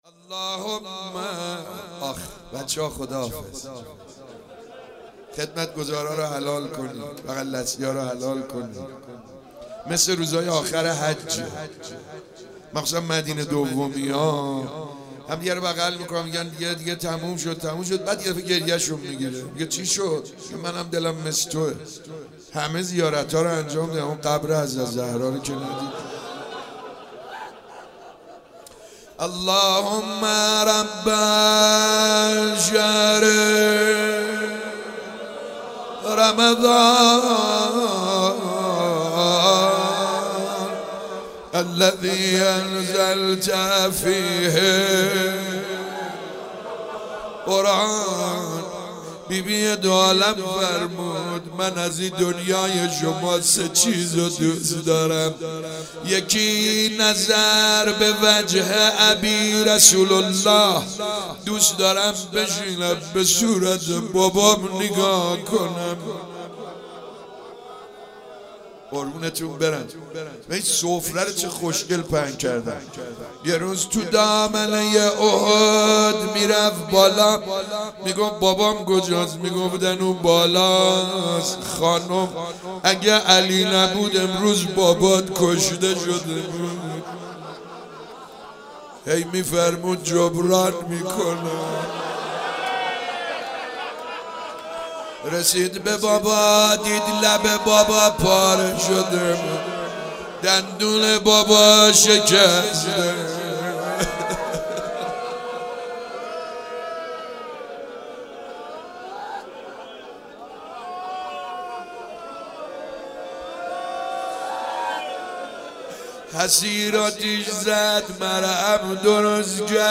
شب 25 ماه مبارک رمضان 96 - روضه خوانی